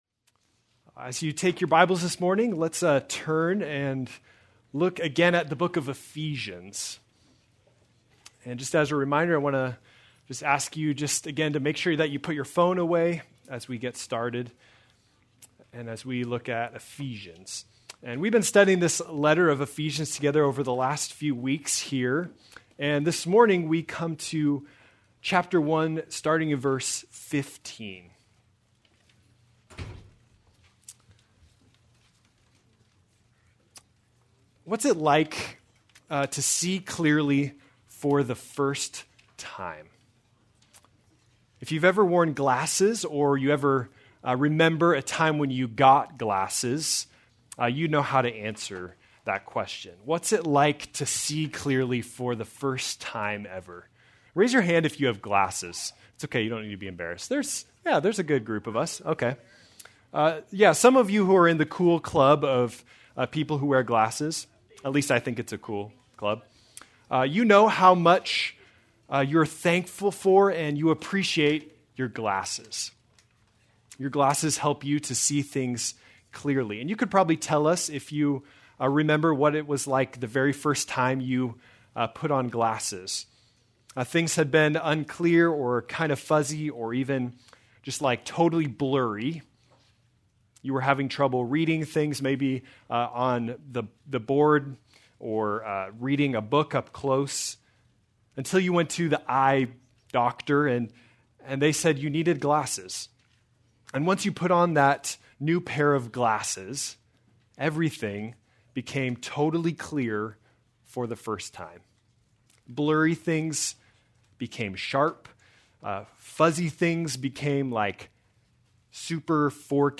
May 11, 2025 - Sermon